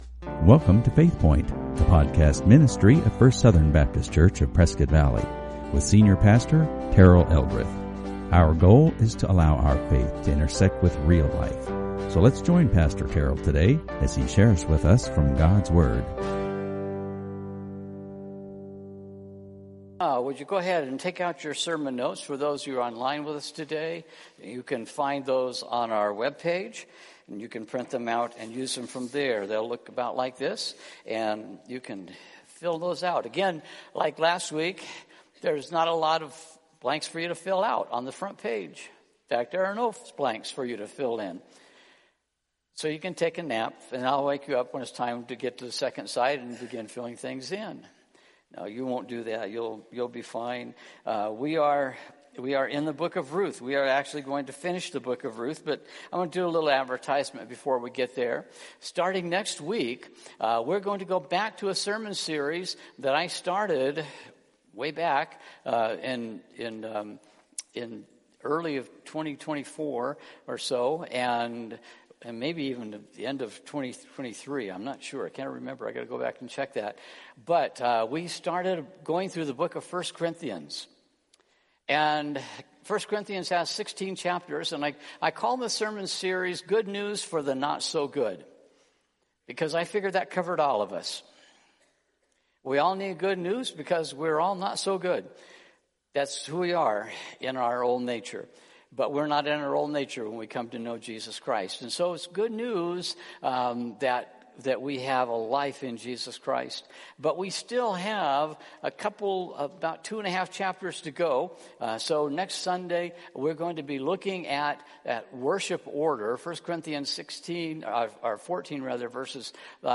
IMMERSE: Messiah Week Two Sermon